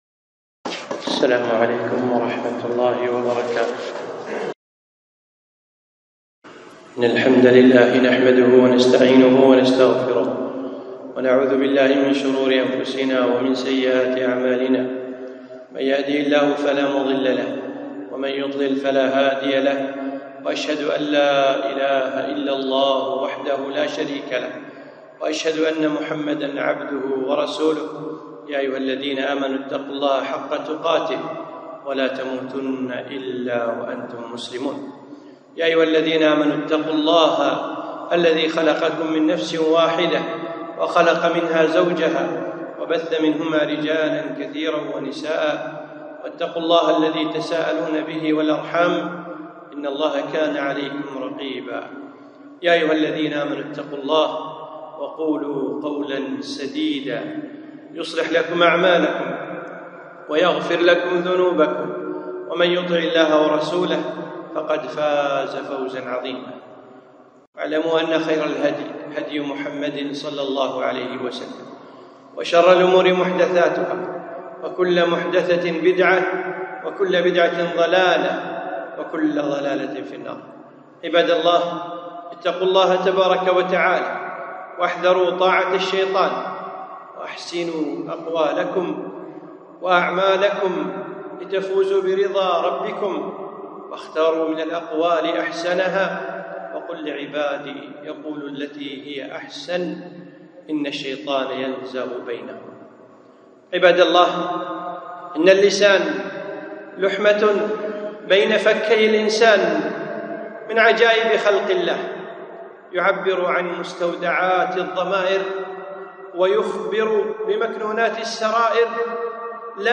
خطبة - خطر اللسان